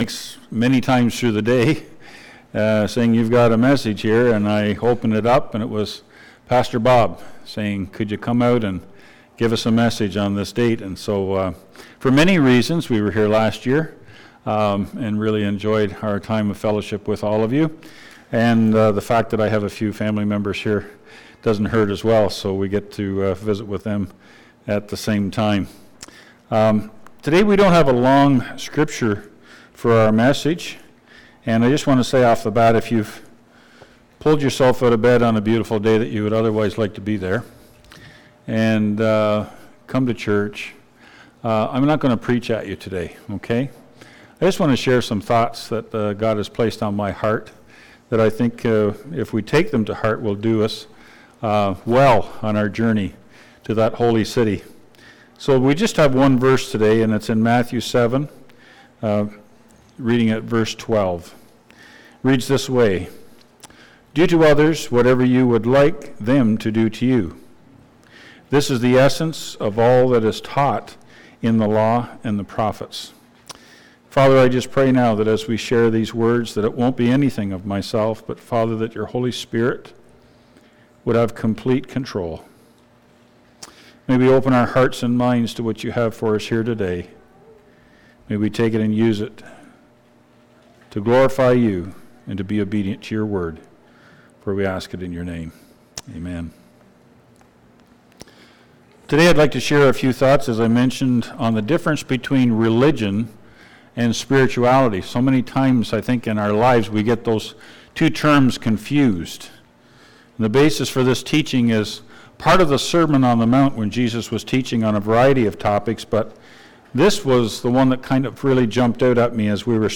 Matthew 18:1-14 Service Type: Sermon